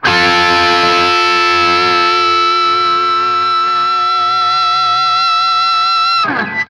TRIAD F   -L.wav